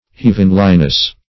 Search Result for " heavenliness" : The Collaborative International Dictionary of English v.0.48: heavenliness \heav"en*li*ness\ (h[e^]v"'n*l[i^]*n[e^]s), n. [From Heavenly .] The state or quality of being heavenly.
heavenliness.mp3